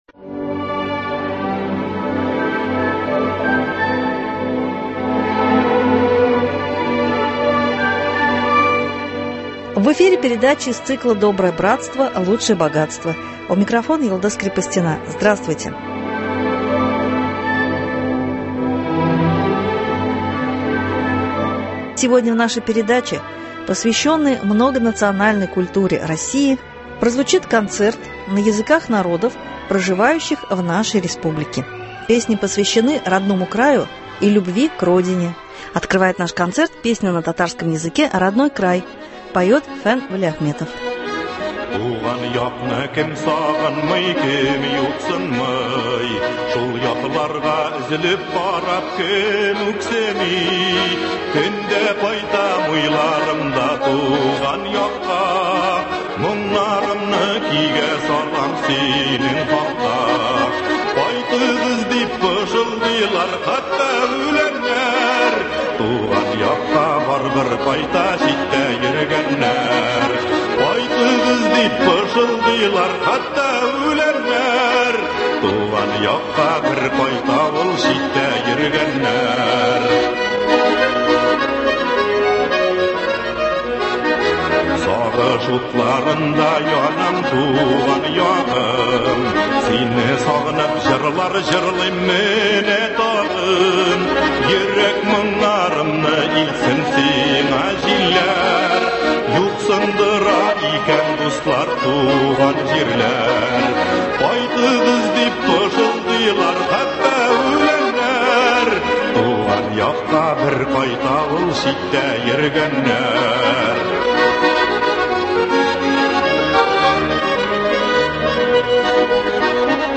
Песни о Родине на языках народов Татарстана.